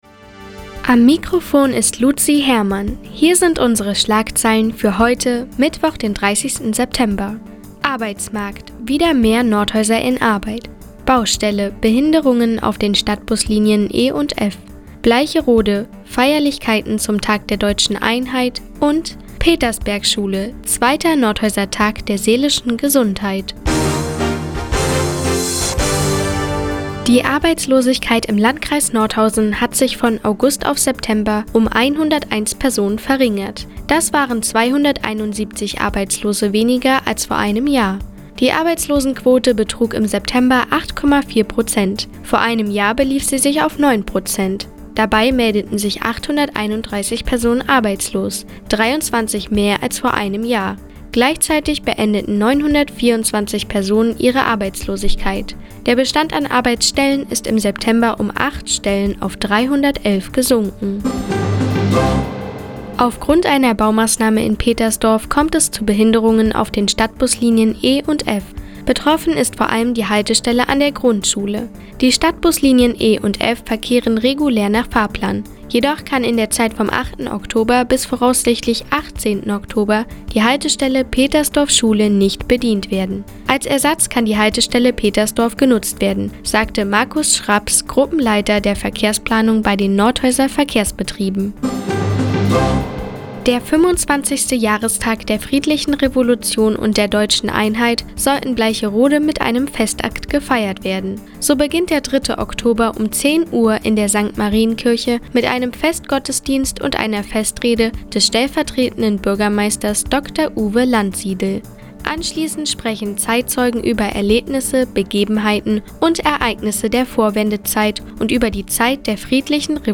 Mi, 17:00 Uhr 30.09.2015 Neues vom Offenen Kanal Nordhausen „Der Tag auf die Ohren“ Seit Jahren kooperieren die Nordthüringer Online-Zeitungen, und der Offene Kanal Nordhausen. Die tägliche Nachrichtensendung des OKN ist jetzt hier zu hören.